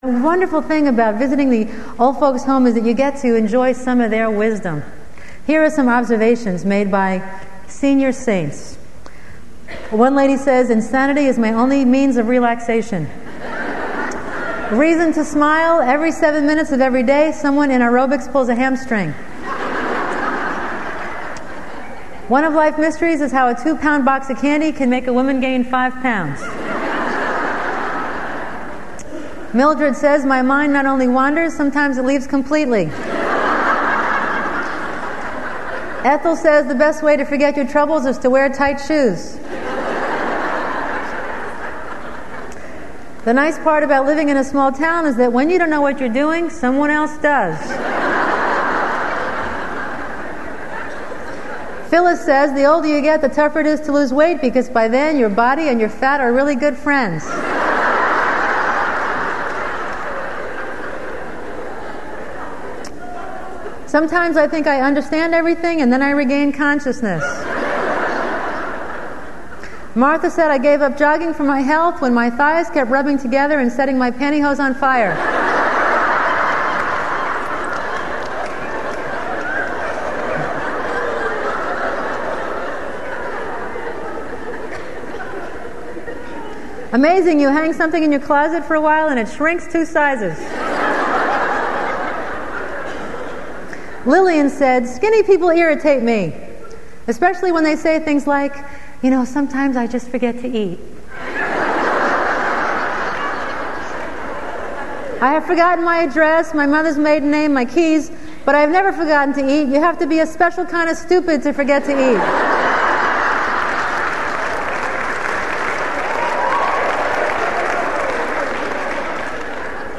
MP3 file is a hilarious comedy routine about wisdom from the Old Folk's Home